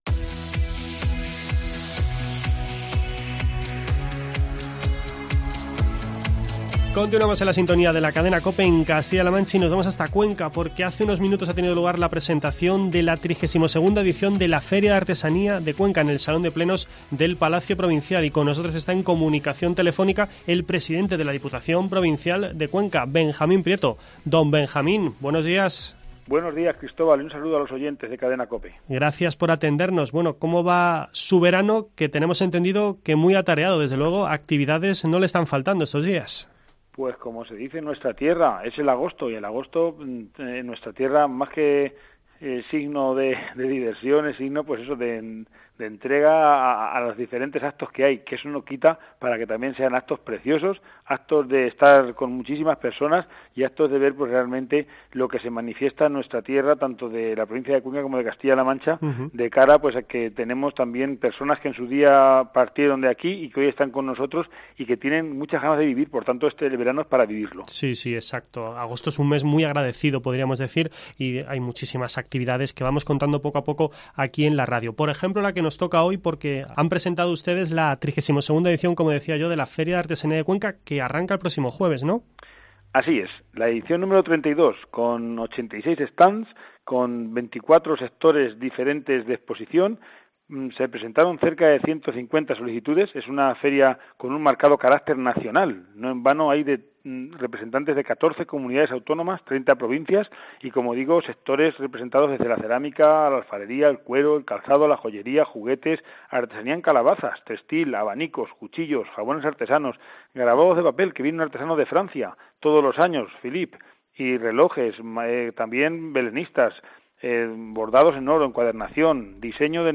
Charlamos con Benjamin Prieto, presidente de la Diputación Provincial de Cuenca